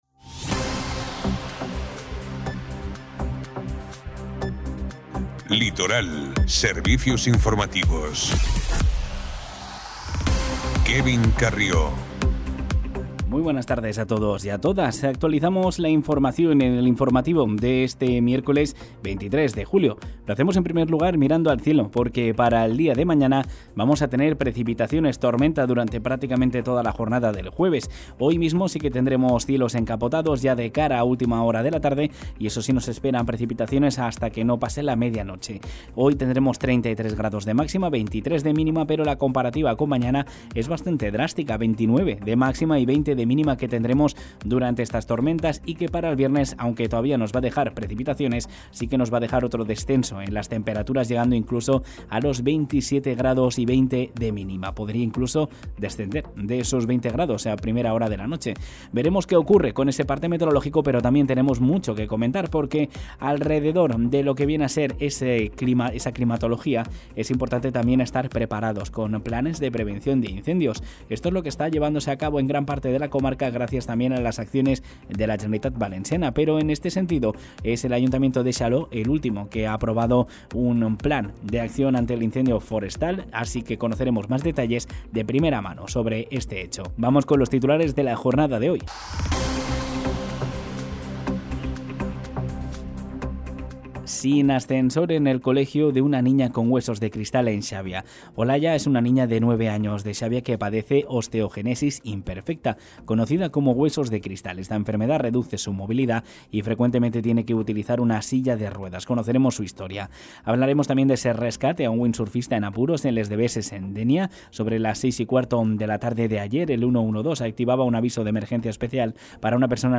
Informativo Radio Litoral 23/07/2025 | Ràdio Litoral